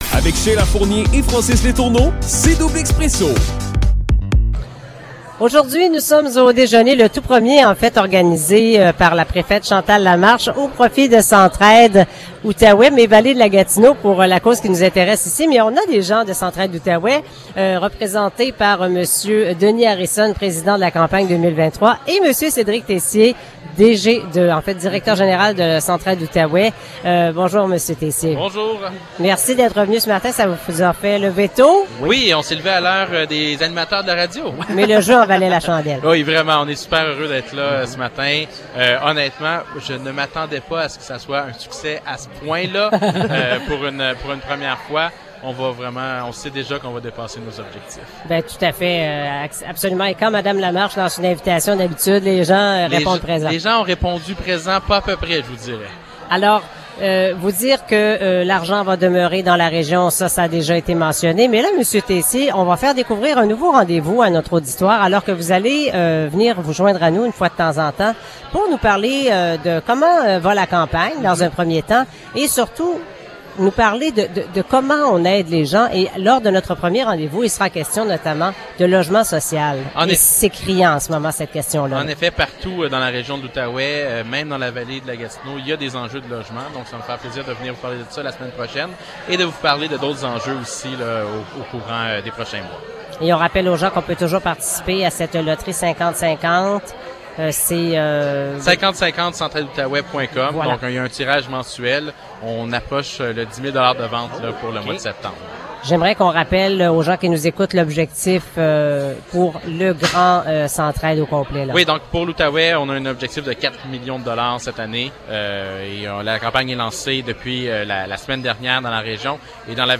Entrevue
en direct du déjeuner de la préfète